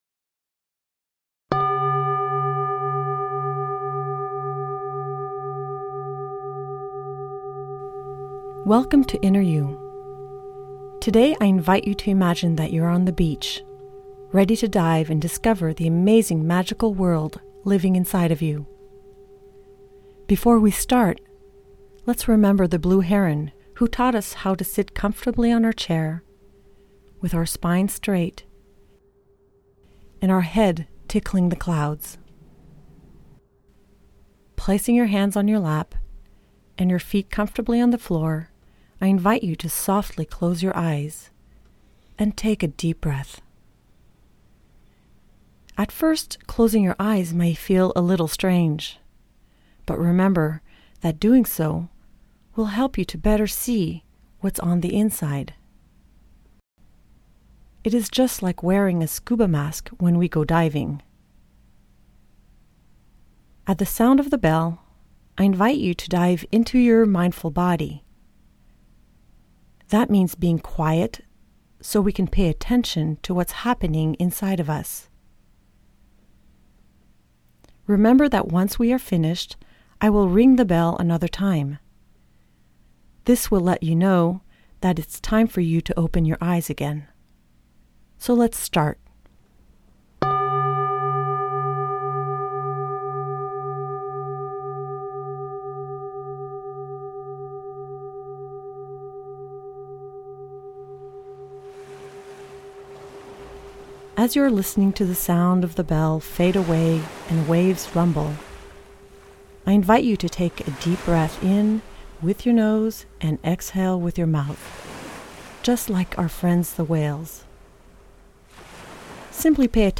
01-InnerU-Week-1-Meditation.mp3